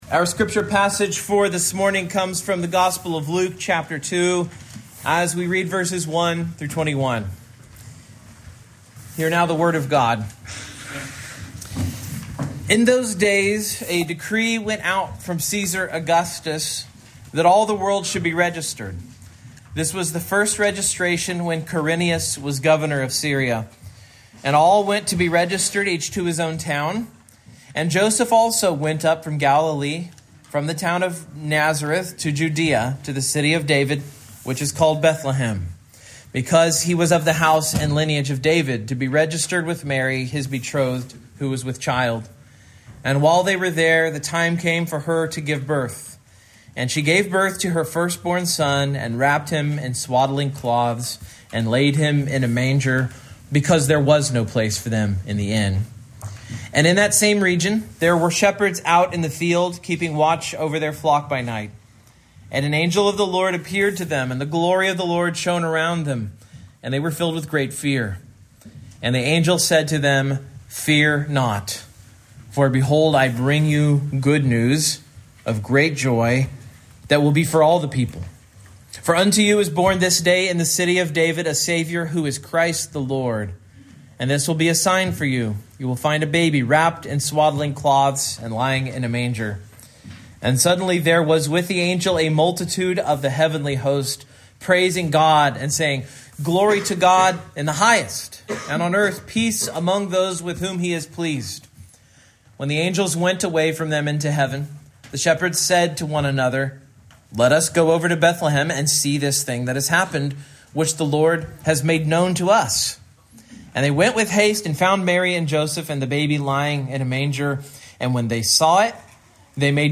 Luke 2:1-21 Service Type: Morning Main Point